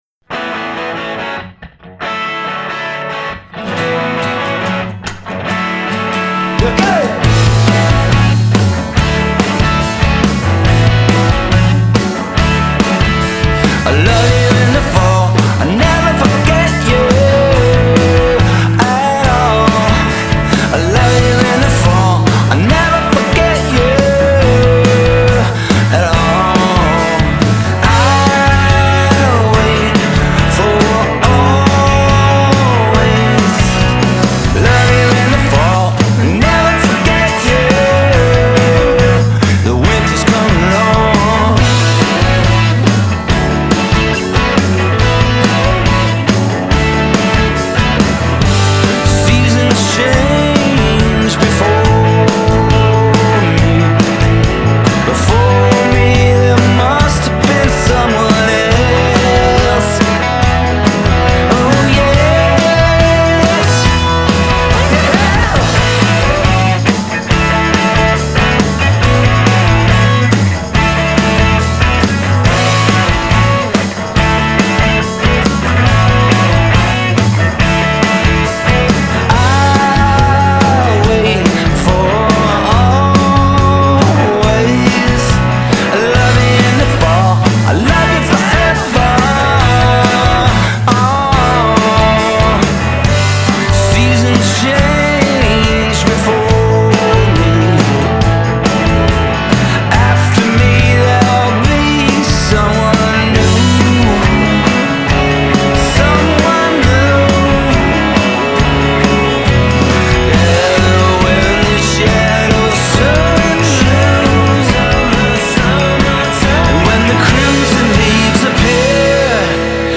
“shambling” guitar driven semi-punk attitude